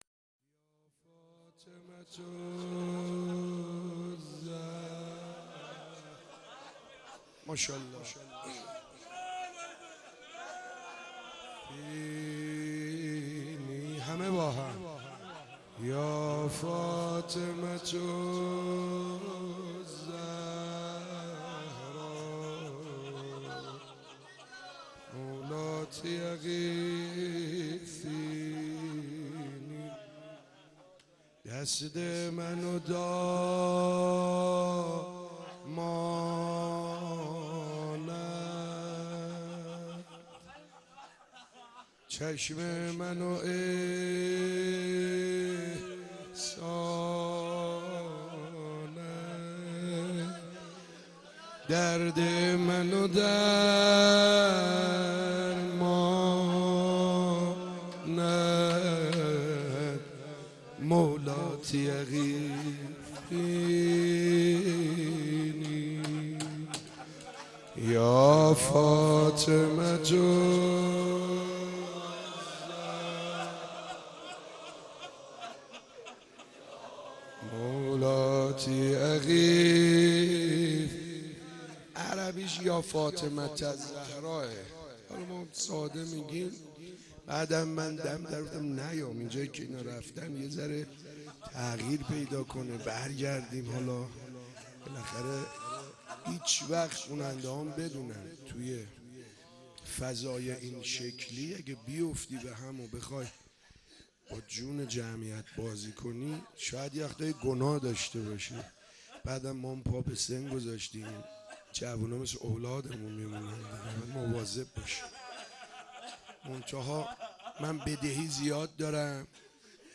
مناجات امام زمان